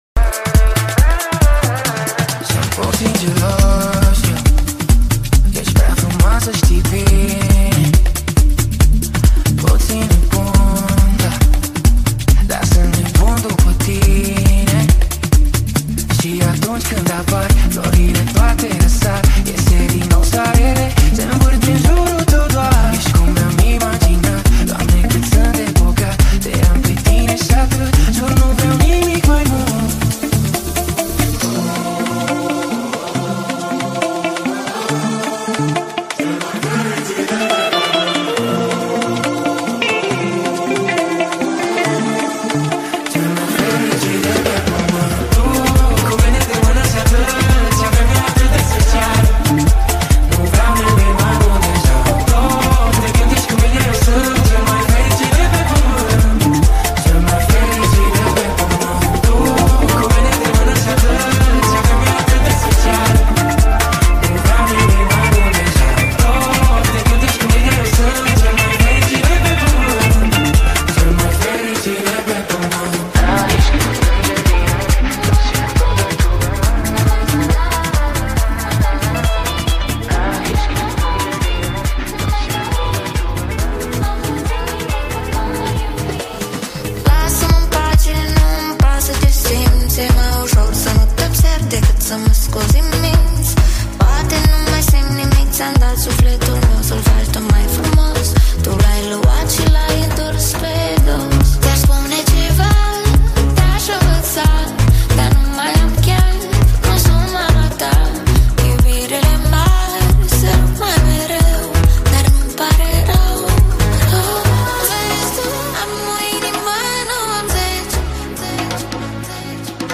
I have made a mix with romanian music.